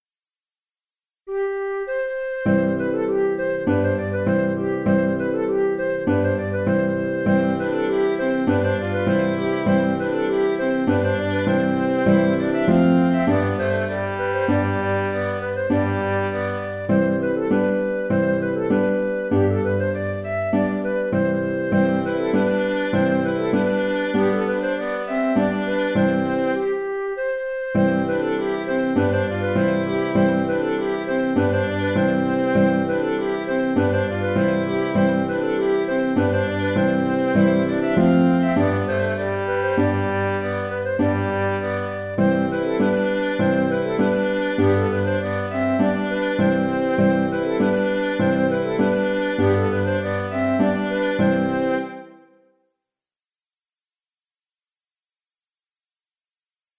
Pieternelle (La) (Scottish) - Musique folk
C’est une danse des Flandres qui a un caractère assez ludique.
Le thème et le contre-chant sont simples et présentent surtout un intérêt par rapport à la danse.